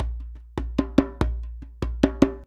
100DJEMB12.wav